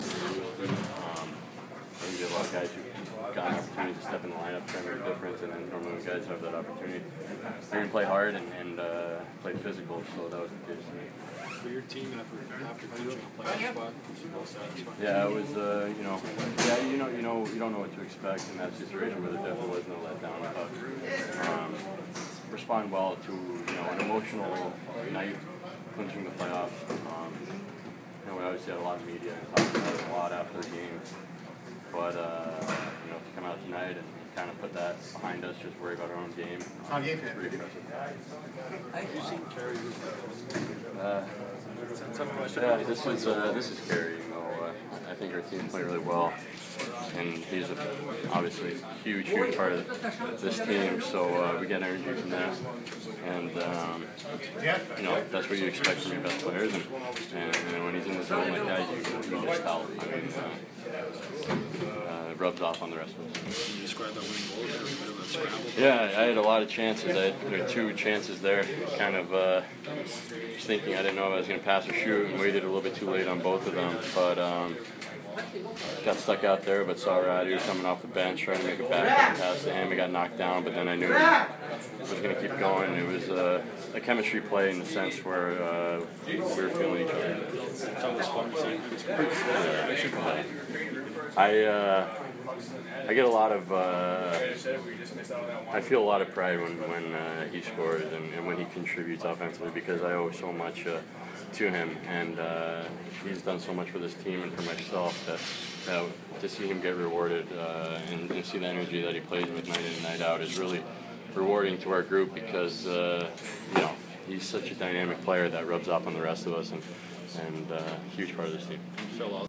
Pacioretty Post Game 4 - 1